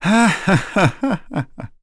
Zafir-Vox_Happy1.wav